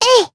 Lewsia_A-Vox_Attack1_jp.wav